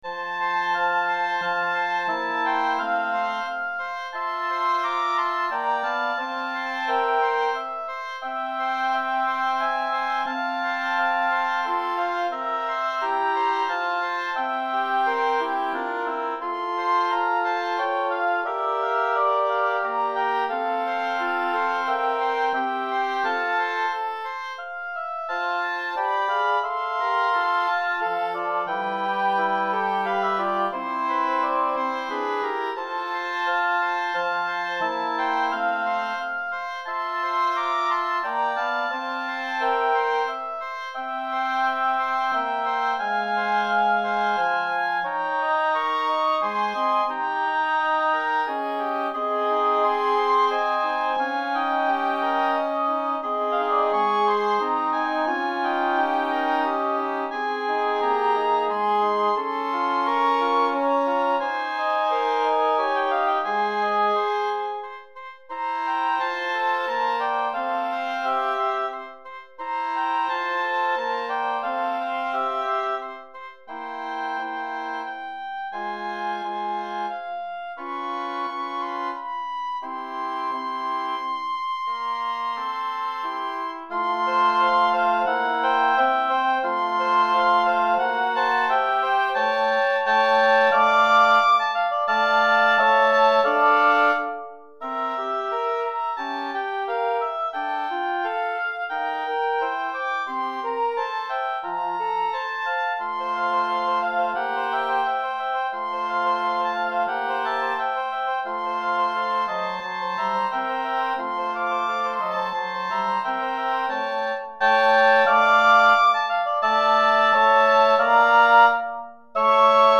3 Hautbois et Cor Anglais